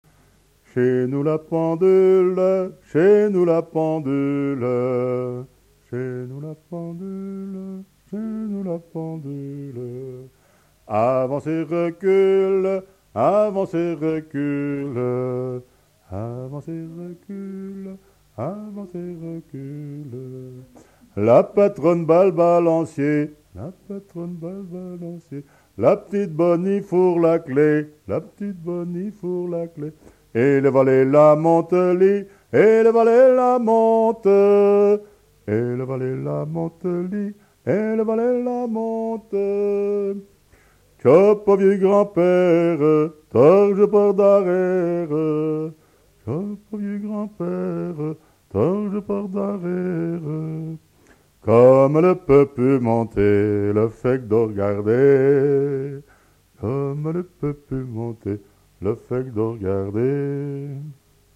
Chaque phrase est bissée et reprise par les choristes
Pièce musicale inédite